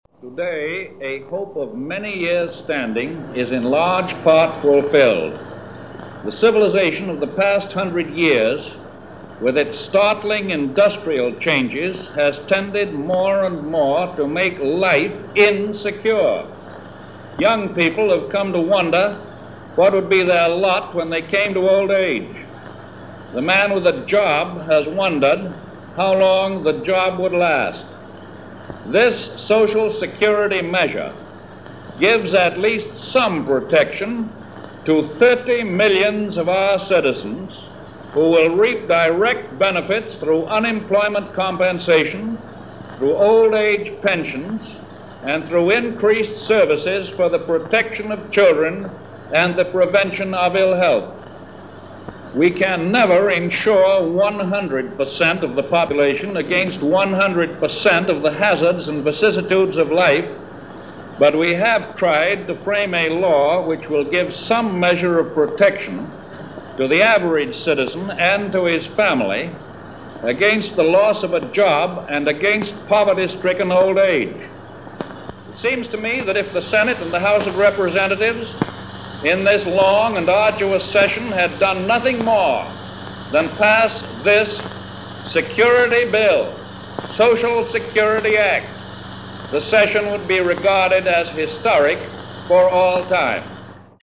President Roosevelt signs The Social Security Act of 1935